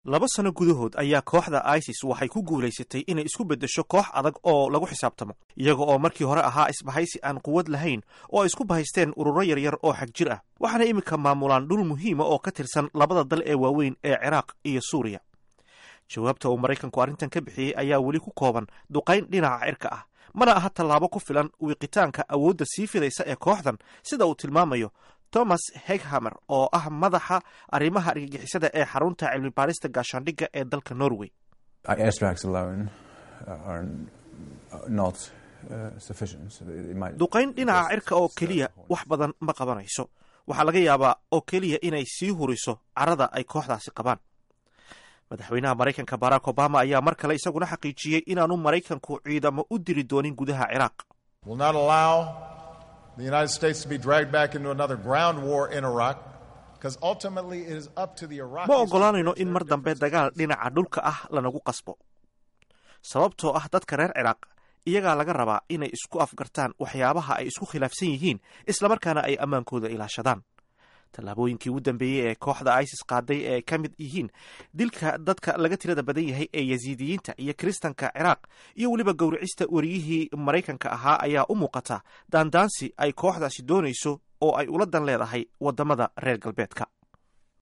Khudbadda Obama